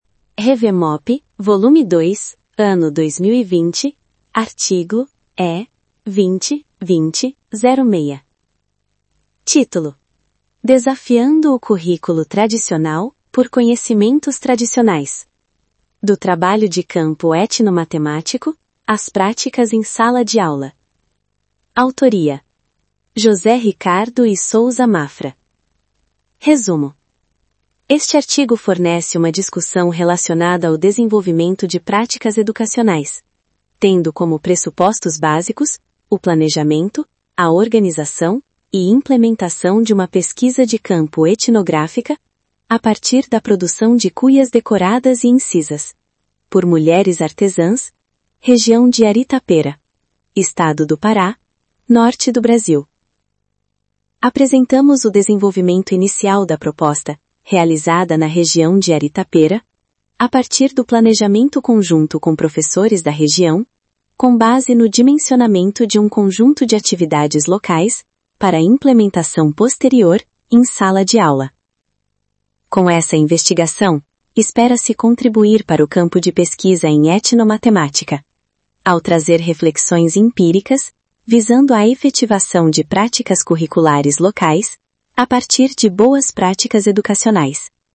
Áudio do resumo